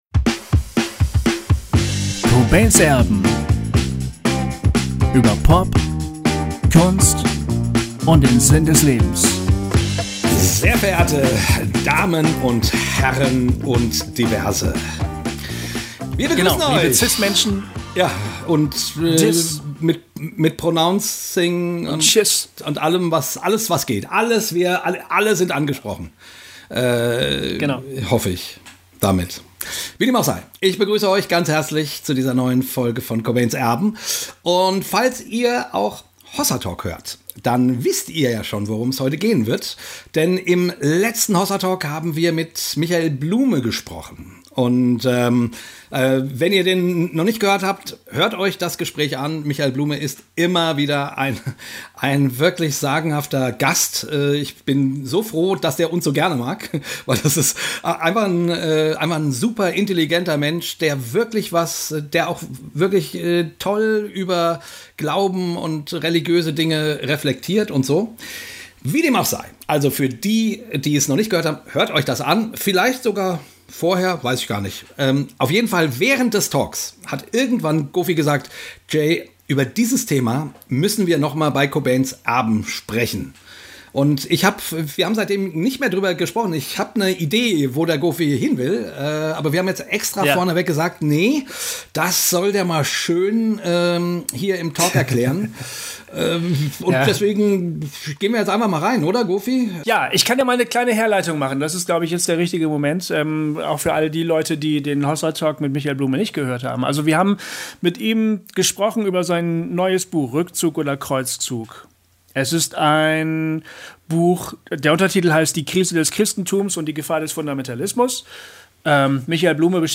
Neulich haben wir uns in unserem anderen Podcast Hossa Talk ausführlich mit ihm unterhalten Und in dieser Folge machen wir zu zweit da weiter, wo wir mit ihm gemeinsam aufgehört haben. Welchen Einfluss haben unsere Alphabete auf unser Denken? Worin liegt der Unterschied zwischen verstehen und erfahren? Und was hat das alles mit Kunst zu tun?